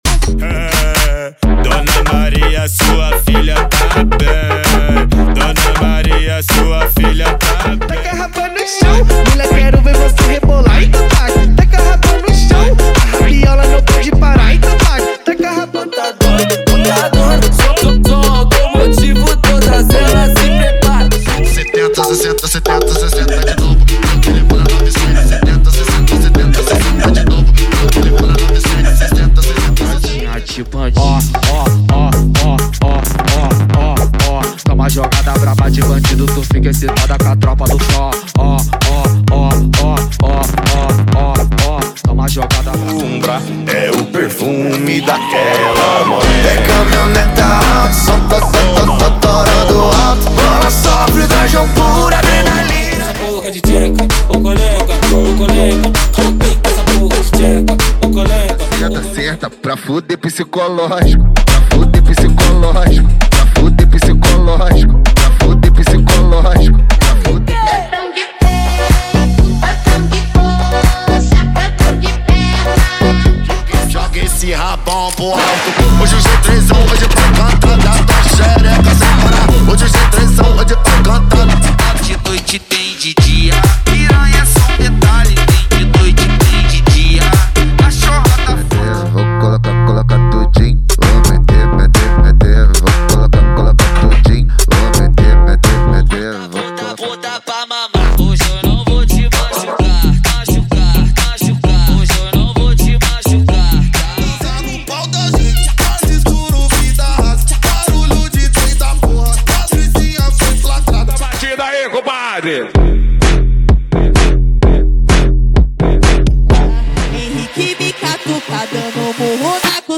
ELETRO FUNK = 50 Músicas
Sem Vinhetas
Em Alta Qualidade